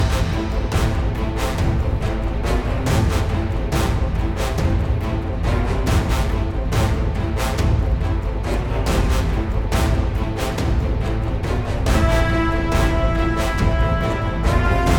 Musique multipistes.